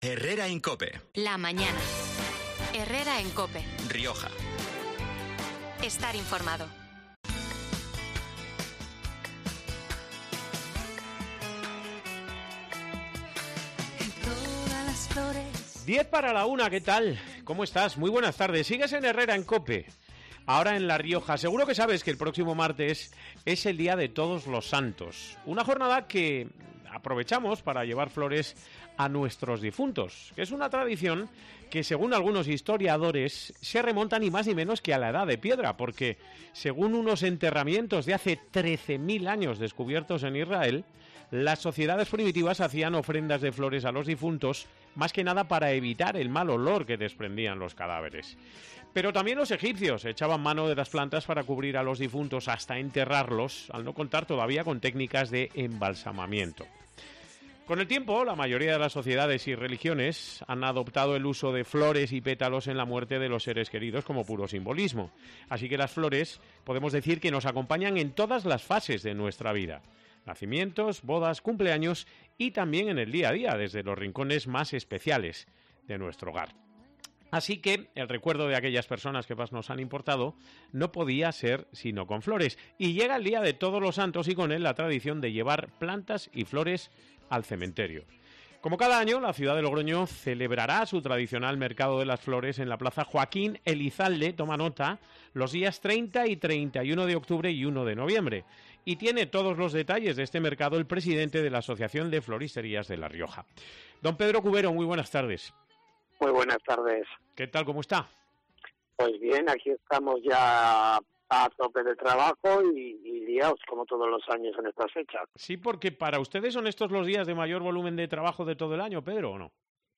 ha pasado este miércoles por los micrófonos de COPE Rioja y ha confirmado que serán siete los establecimientos participantes en el mercado y que